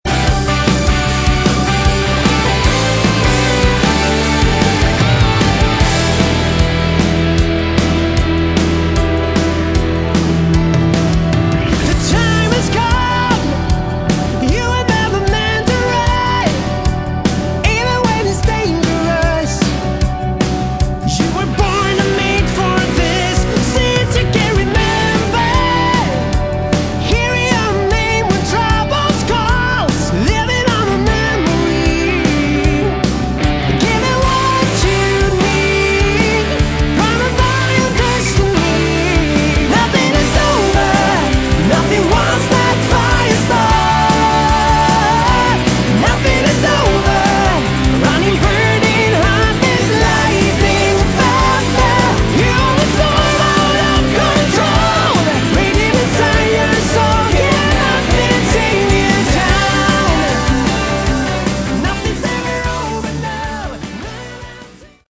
Category: AOR / Melodic Rock
lead vocals
guitars, backing vocals, keyboards
bass
drums